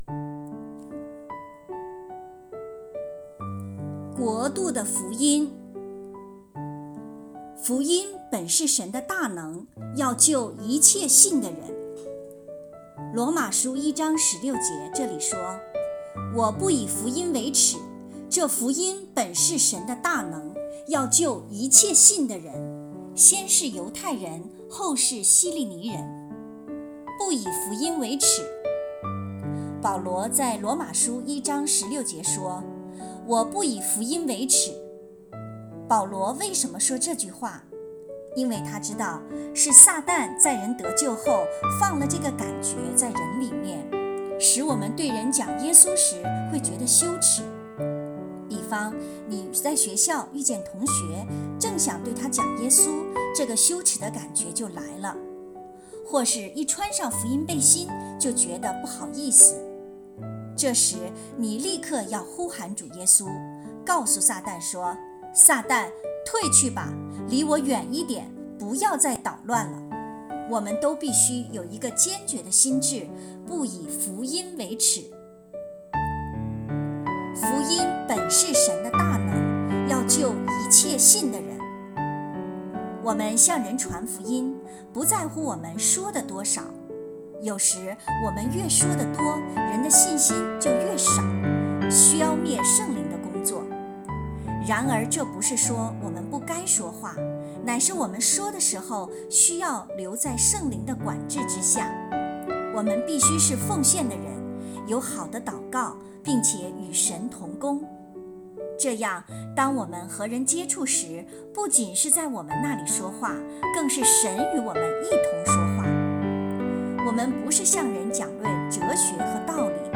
有声版